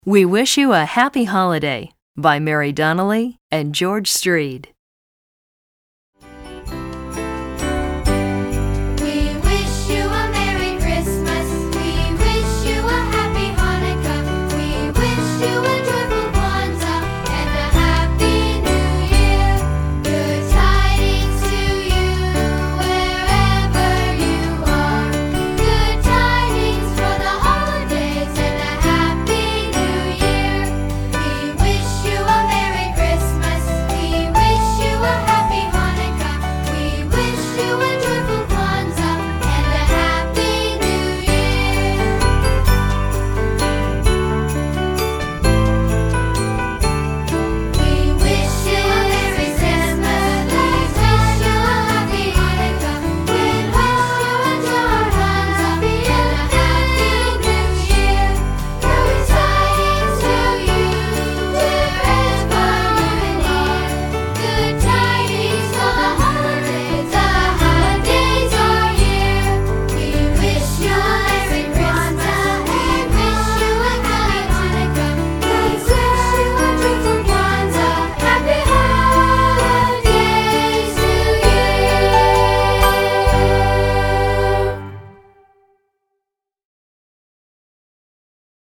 Voicing: Accompaniment CD